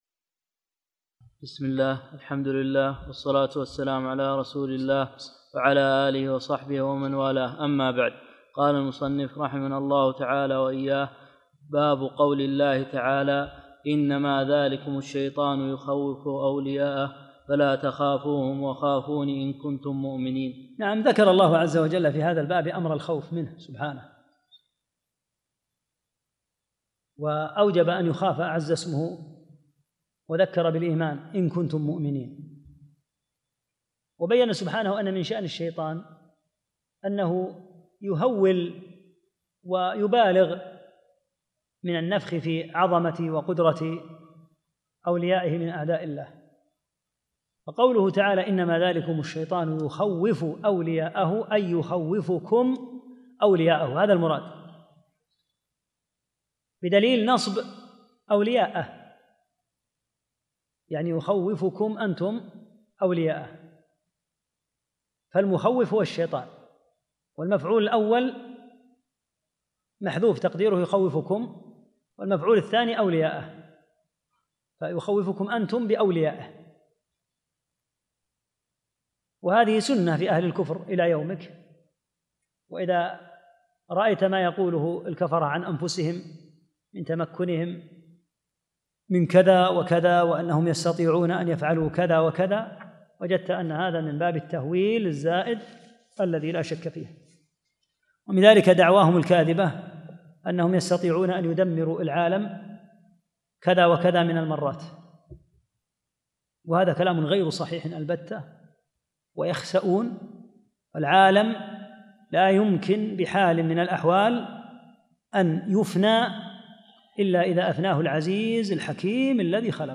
31 - الدرس الحادي والثلاثون